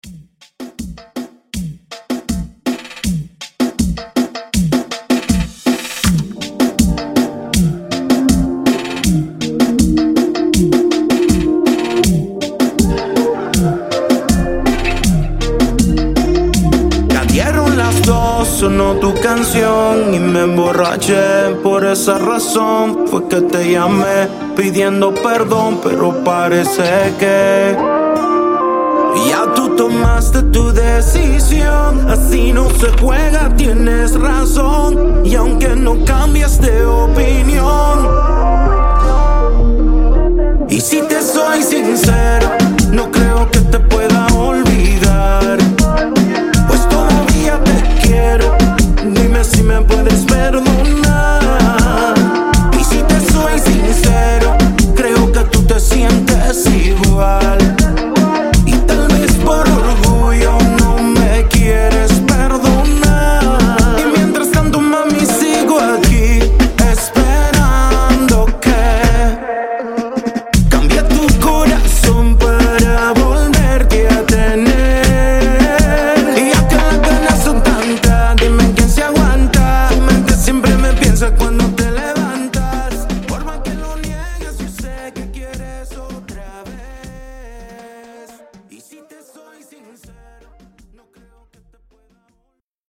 Genres: RE-DRUM , TOP40
Clean BPM: 77 Time